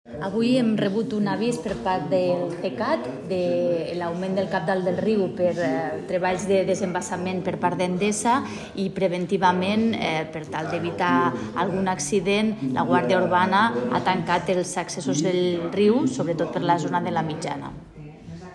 Fitxers relacionats Tall de veu de la tinenta d'alcalde i regidora de Seguretat, Mobilitat i Civisme, Cristina Morón, sobre les mesures preventives per l'increment del cabal del Segre a Lleida (295.0 KB)